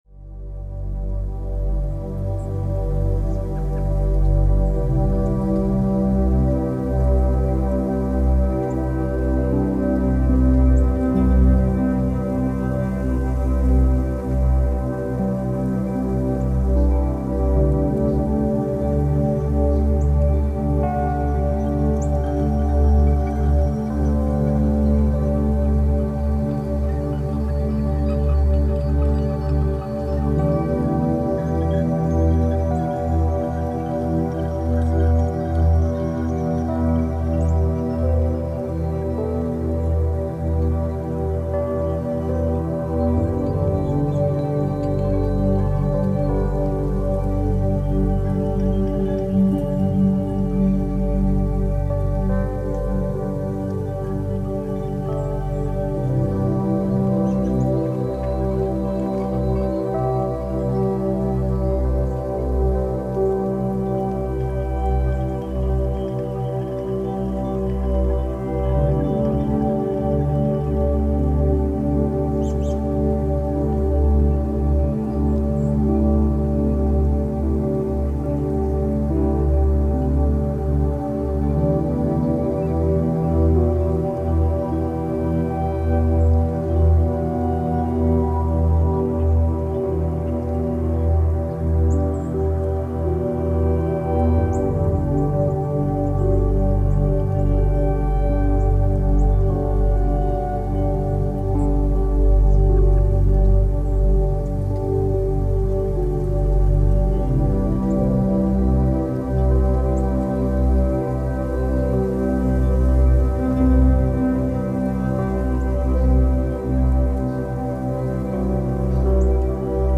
La fréquence 126,22 Hz détoxe le systeme lamphatique